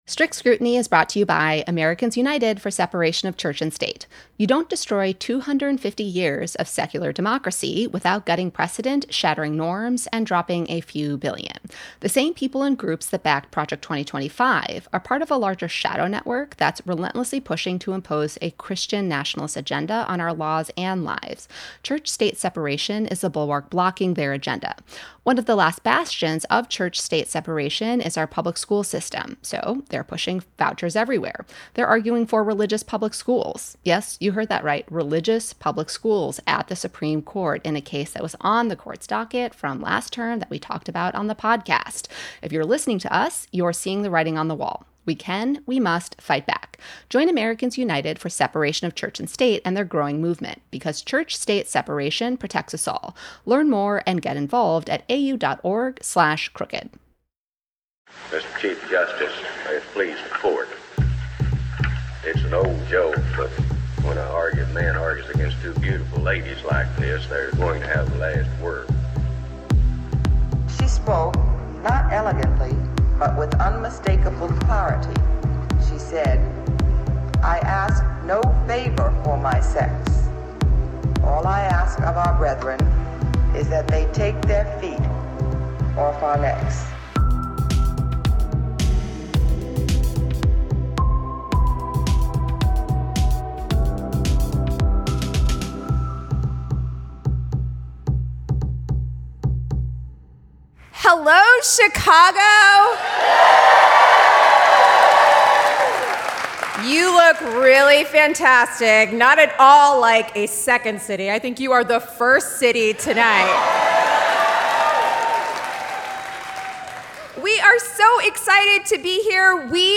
This episode was recorded live at the Athenaeum Center in Chicago.